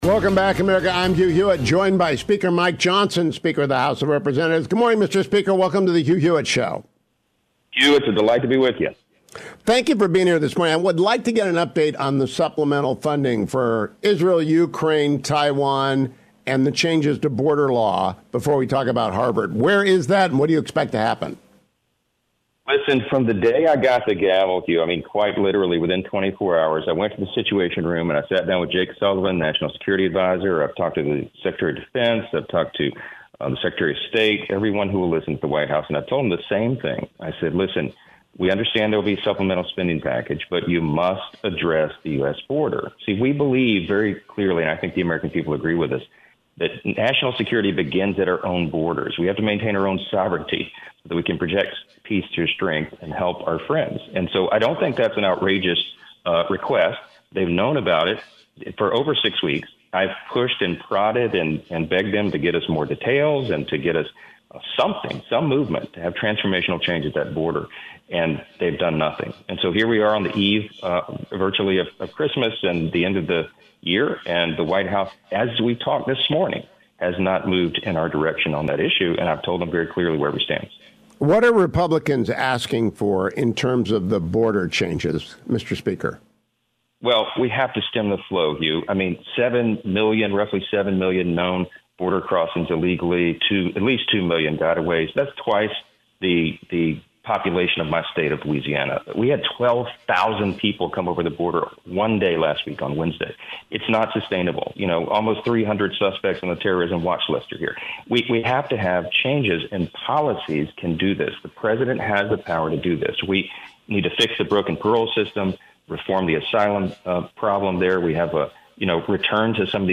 House Speaker Mike Johnson (R-LA) joined me this morning to discuss the paralysis among Democrats –and the absence of the president from the negotiations– over the supplemtal to fund Israel, Ukraine, Taiwan and make border reforms: